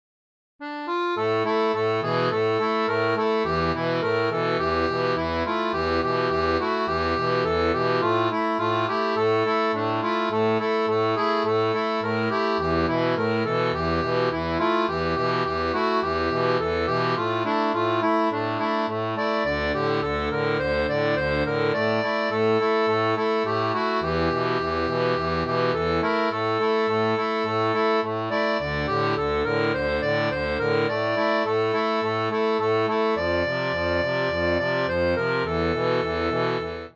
Chanson française